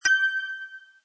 countdown.mp3